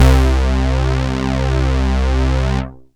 ANALOG 1 2.wav